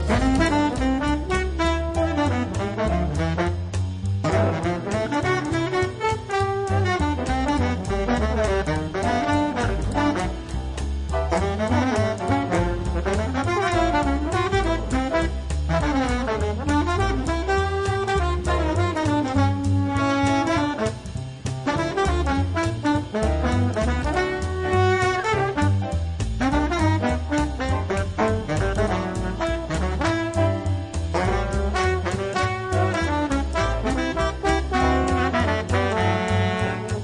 Recorded Eastcote Studios, West London 2006